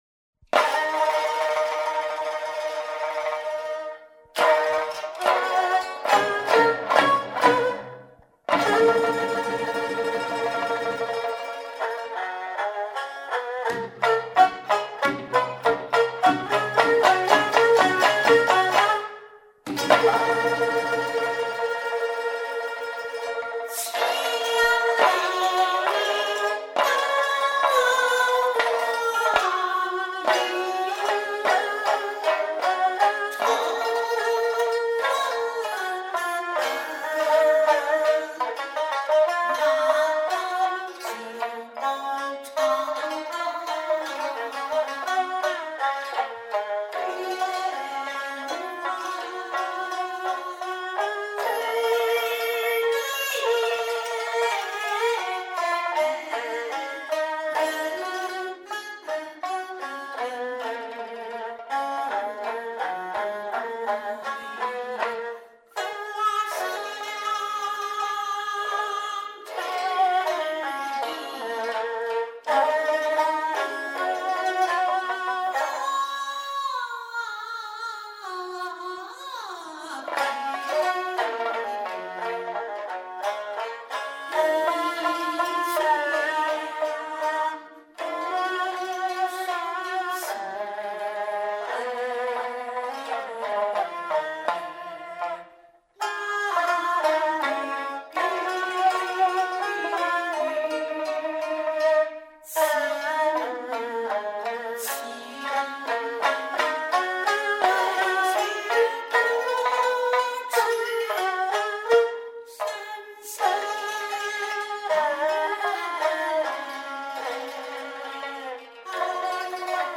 还是用消音方法来试试。